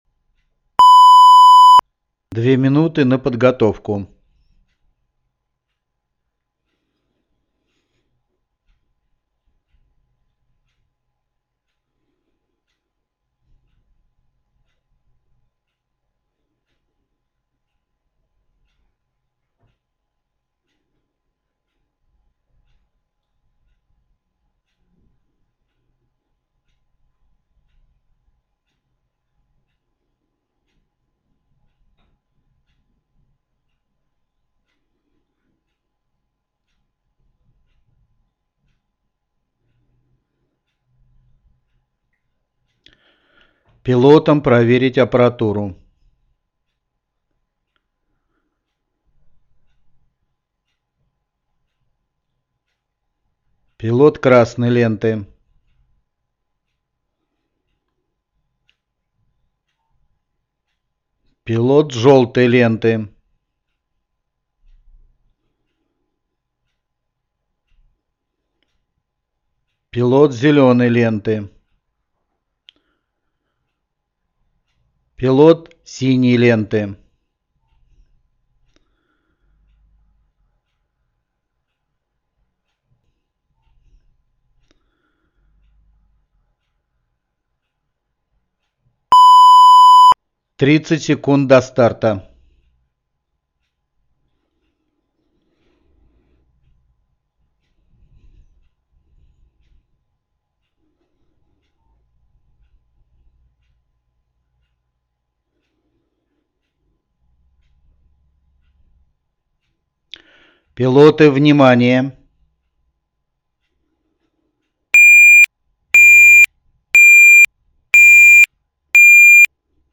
Файл звукового сопровождения ведения старта
start_command.mp3